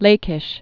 (lākĭsh)